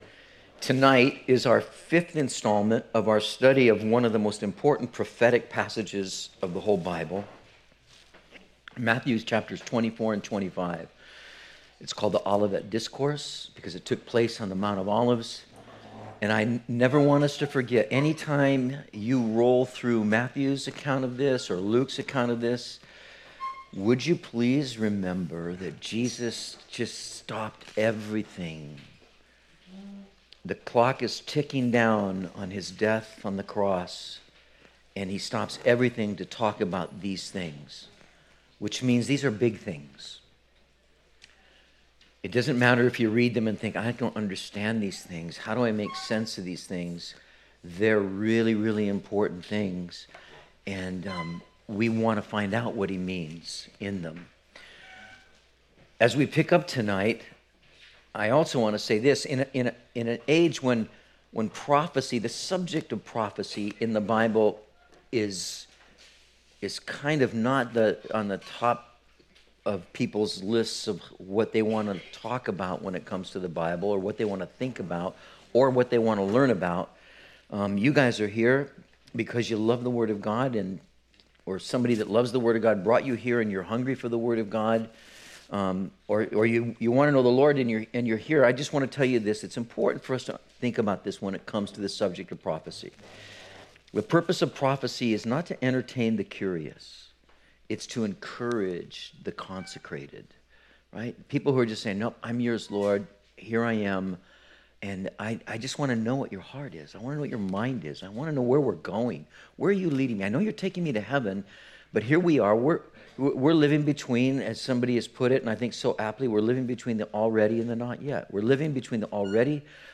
03/25/19 Lessons from the Fig Tree and the Days of Noah - Metro Calvary Sermons